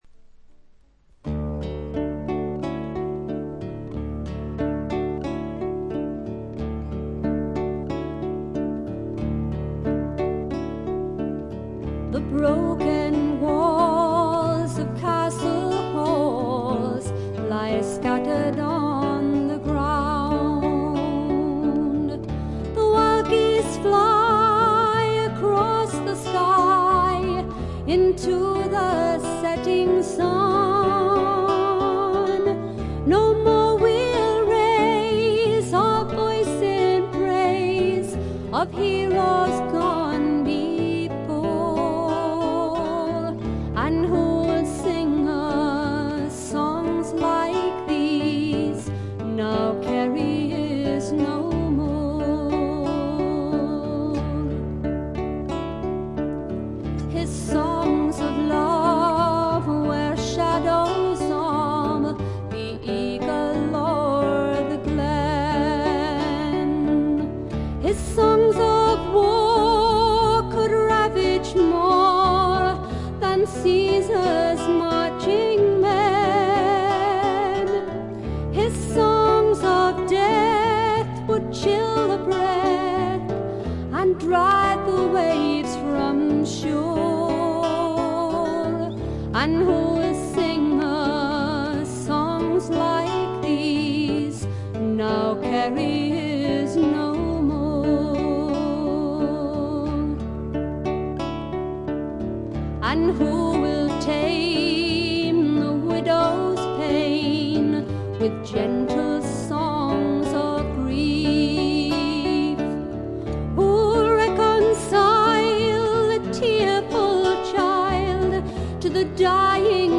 英国の男女フォーク・デュオ
しみじみとした情感が沁みてくる歌が多いです。